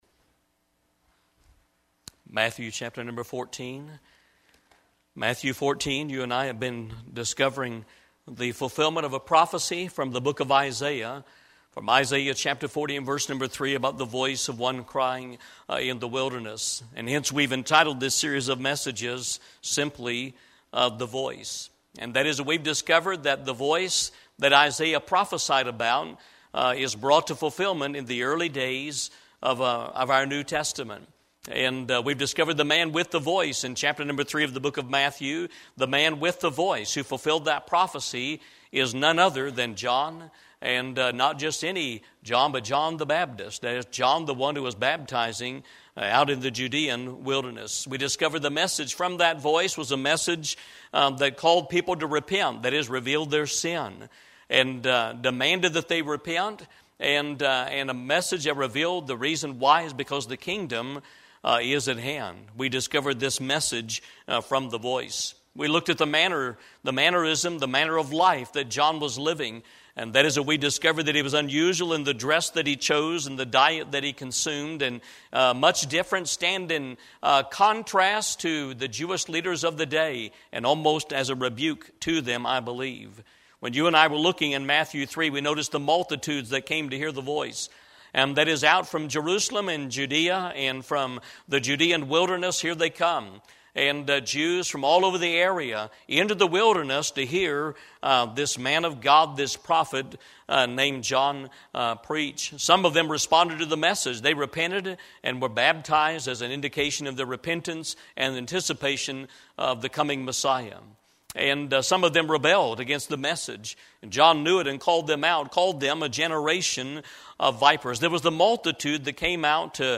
Eastern Gate Baptist Church - The Voice 17